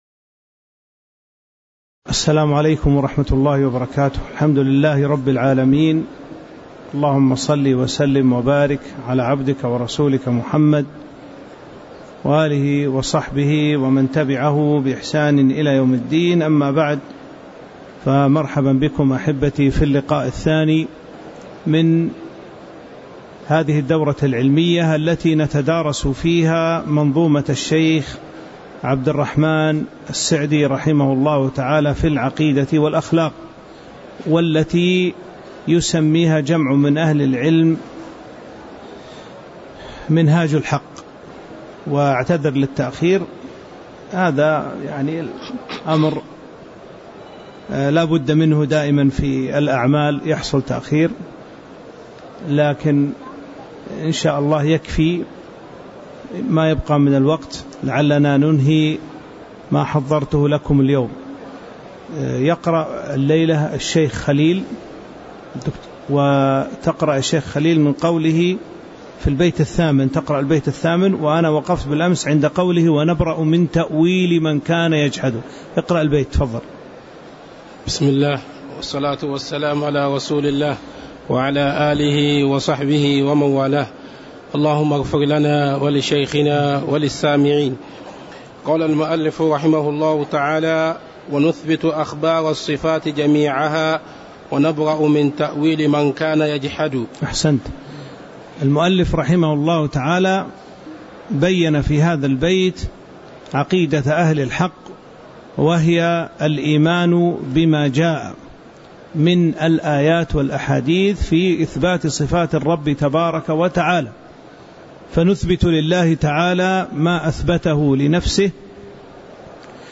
تاريخ النشر ١٢ محرم ١٤٤٦ هـ المكان: المسجد النبوي الشيخ